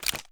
Weapon_Foley 04.wav